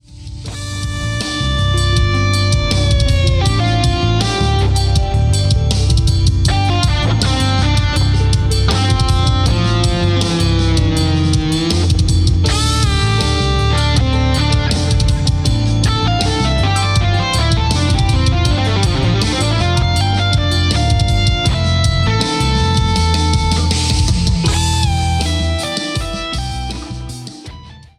raw - mix - master
aruna-24-bit-master-hemsidan-Ausgang-Stereo-Out.wav